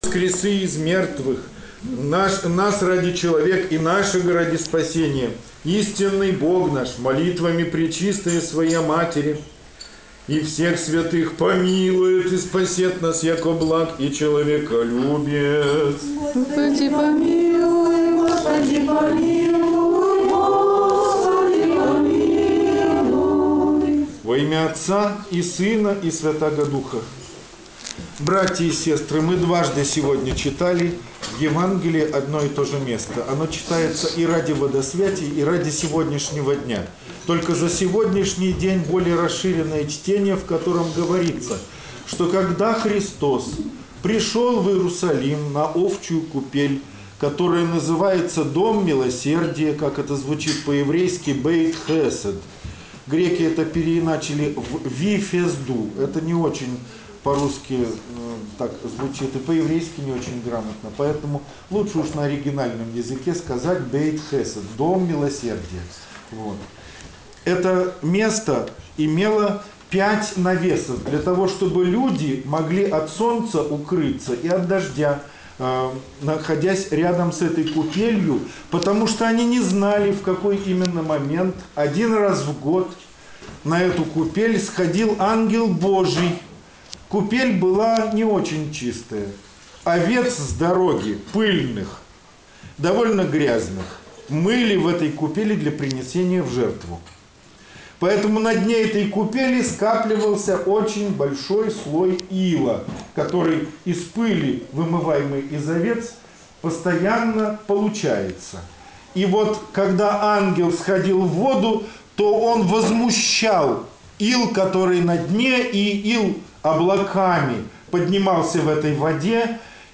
Проповедь в храме св. кн. Владимира в поселке им. Свердлова 3 мая 2015 года. Неделя 4-я по Пасхе, о расслабленном.